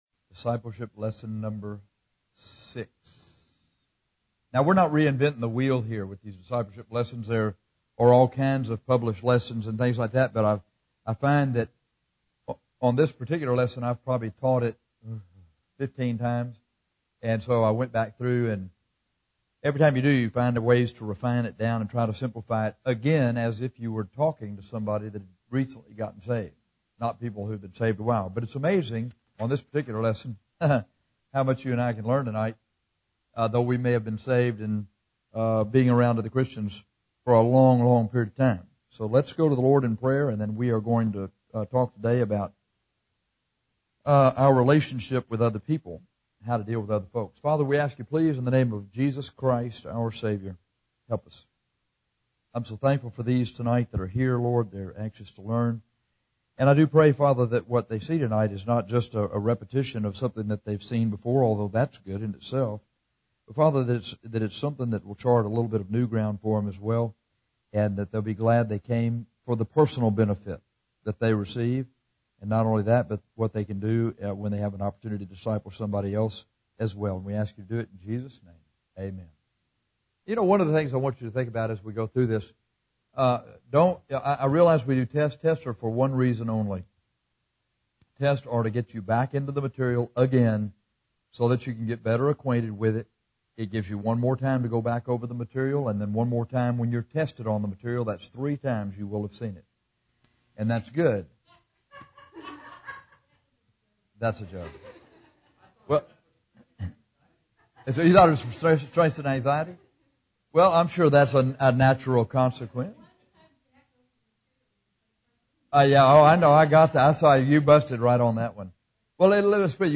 Discipleship Lesson #6 – Dealing With Others